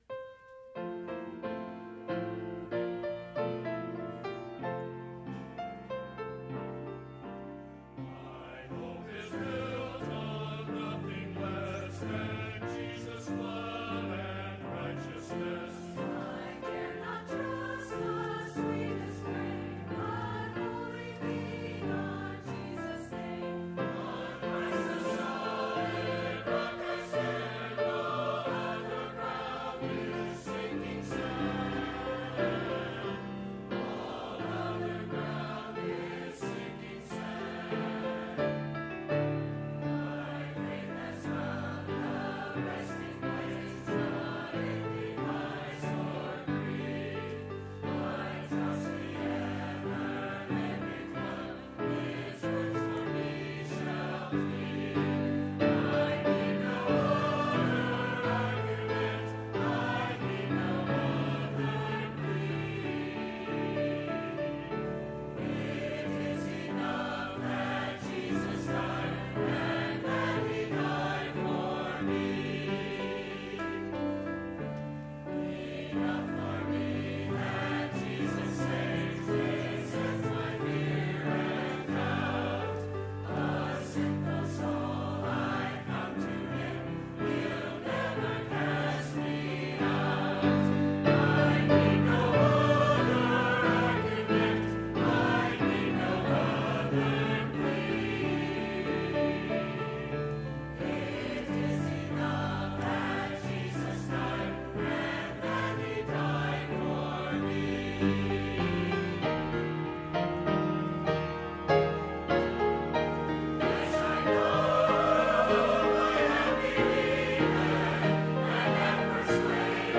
“Faith Medley” ~ Faith Baptist Choir
faith-medley-choir-cut.mp3